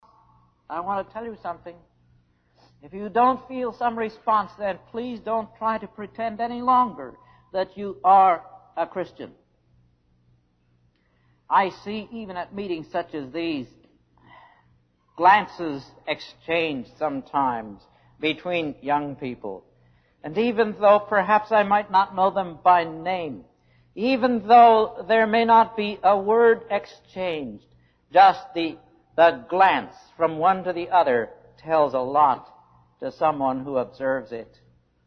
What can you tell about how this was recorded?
They go from the 1960s to the 80s, are of varying degrees of sound quality, but are pretty much all giving exactly the same message, despite there being about sixty of them.